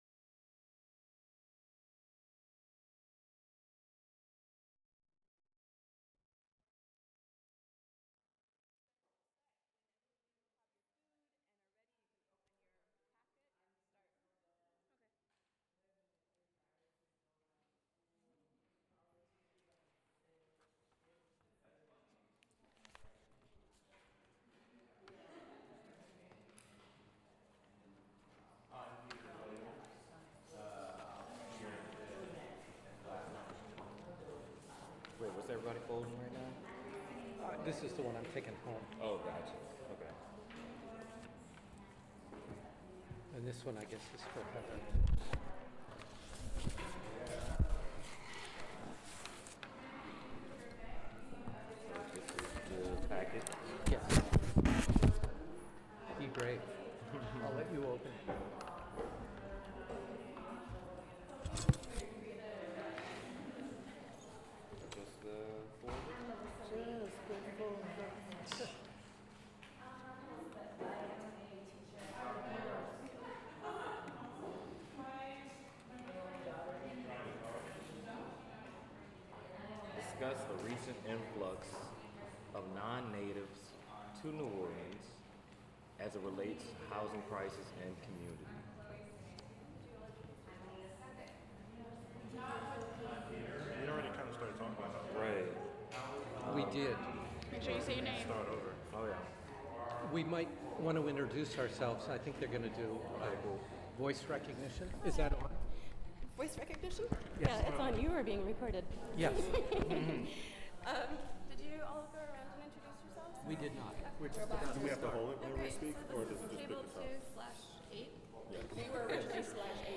sound recording-nonmusical
oral history